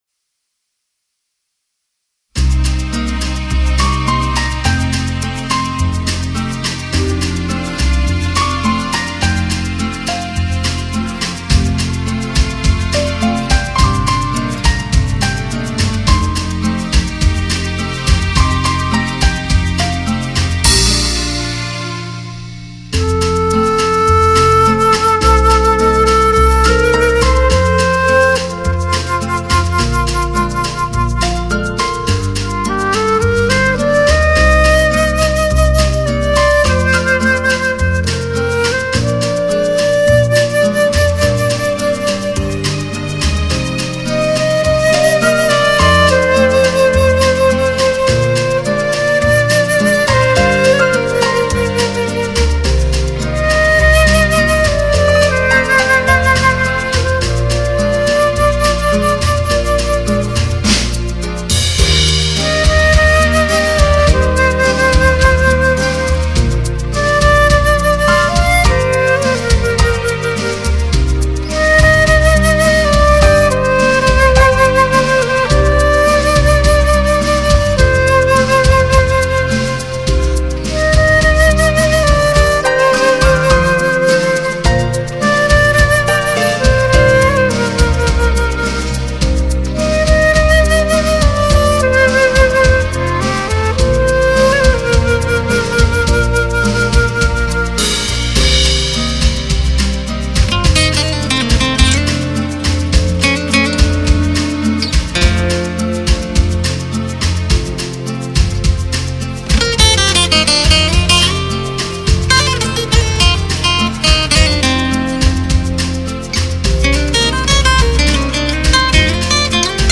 类型: 轻音乐